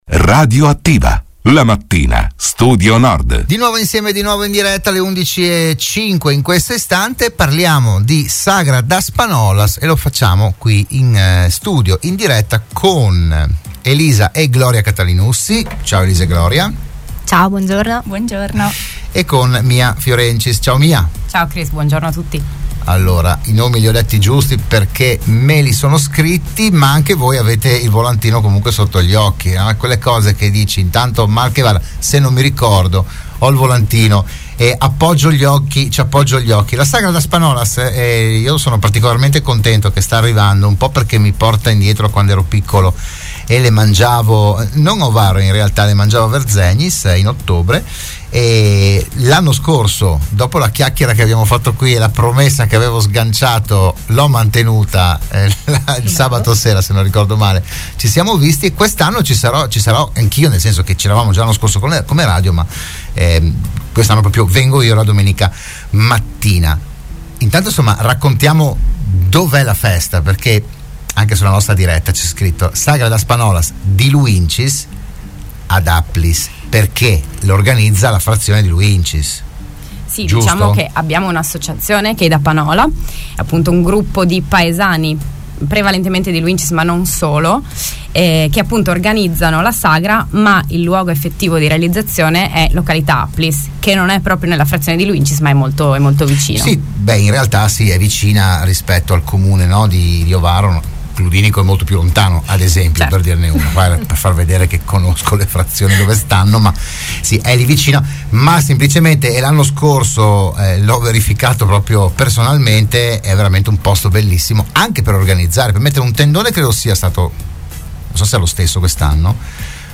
L'intervento di stamattina a Radioattiva